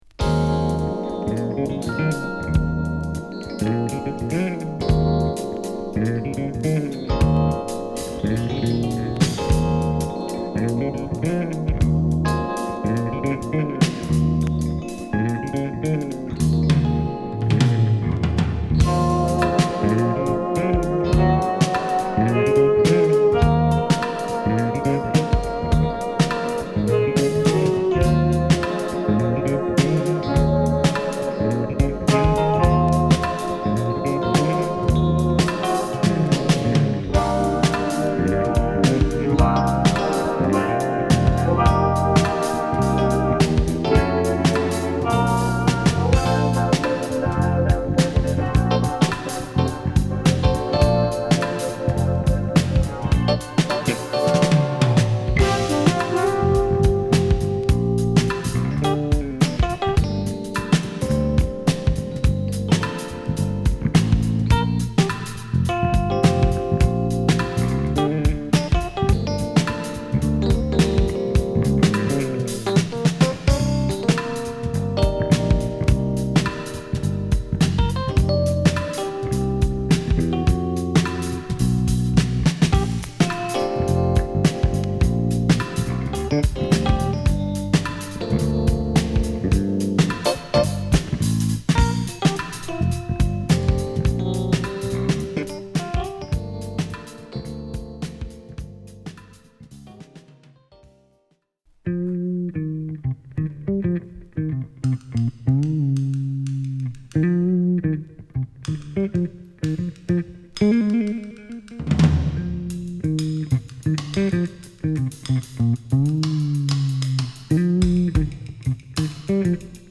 ブラジリアン・フュージョン